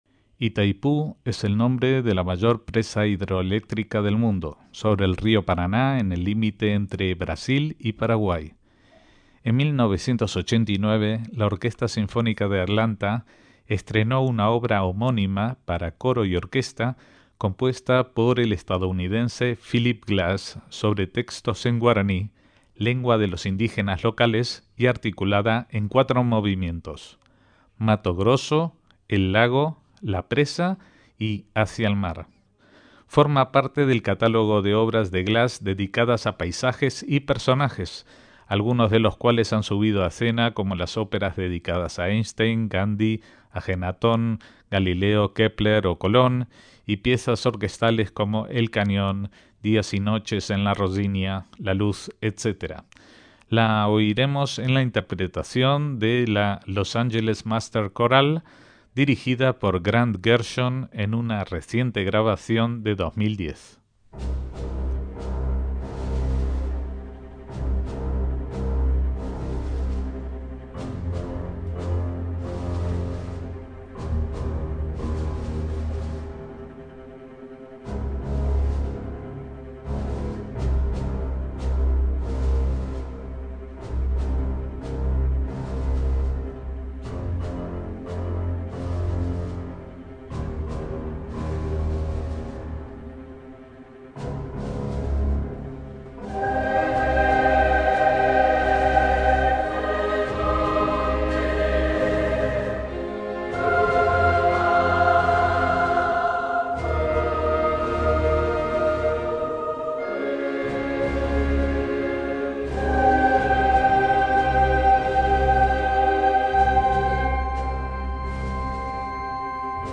MÚSICA CLÁSICA
para orquesta y coro
obra sinfónica